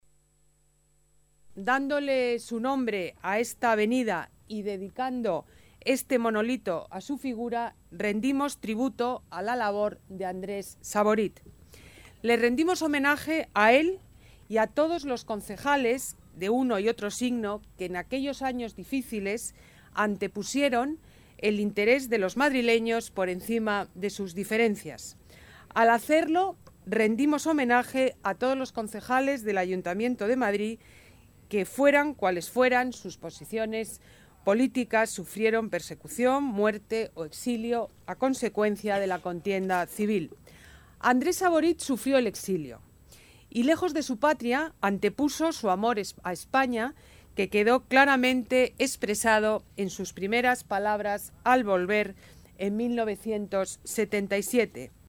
Nueva ventana:Declaraciones de la alcaldesa de Madrid, Ana Botella